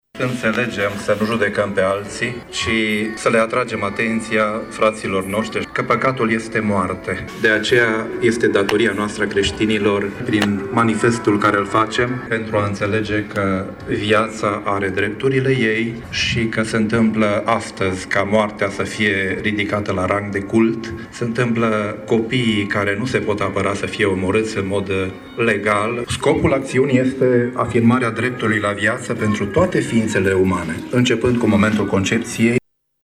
Evenimentele au debutat de la ora 10,00 cu o liturghie celebrată pentru copiii nenăscuți la Biserica greco-catolică ”Bunavestire”, iar de le ora 12.00, organizatori au rostit scurte alocuțiuni în Piața Teatrului.